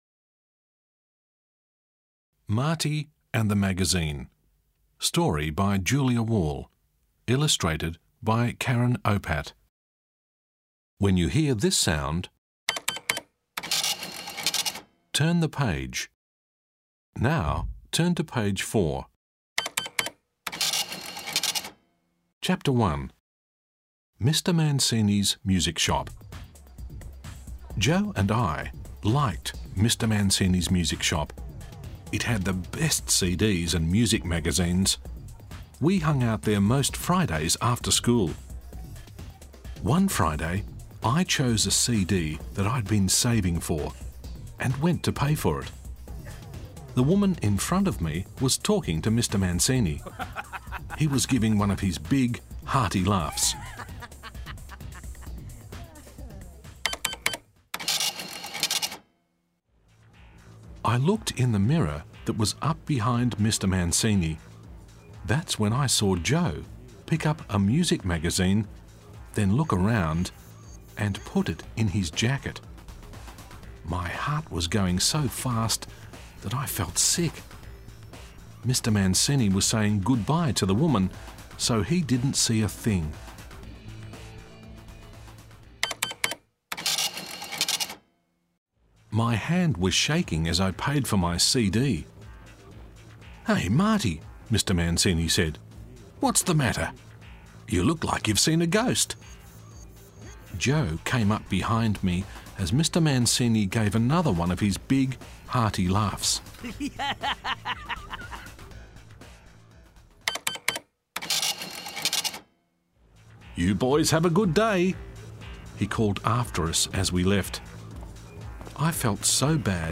Type : Short Story